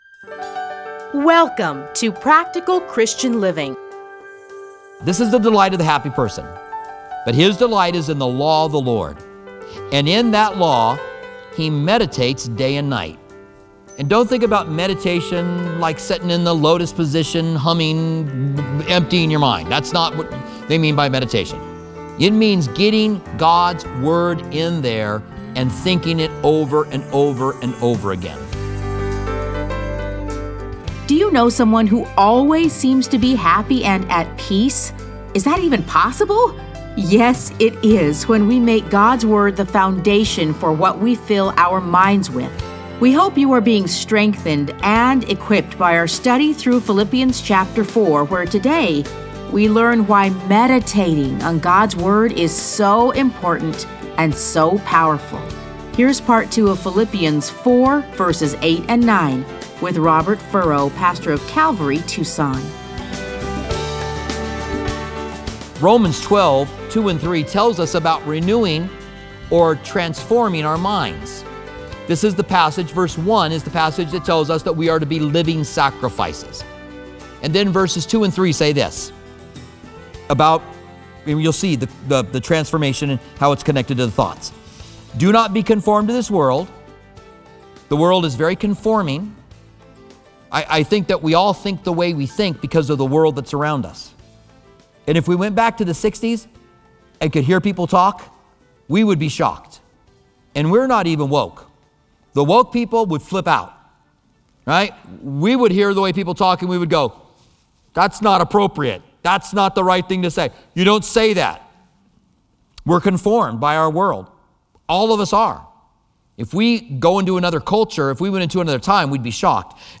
Listen to a teaching from A Study in Philippians 4:8-9.